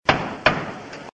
JUDGE GAVEL TWO TIMES.mp3
Original creative-commons licensed sounds for DJ's and music producers, recorded with high quality studio microphones.
judge_gavel_two_times_nmq.ogg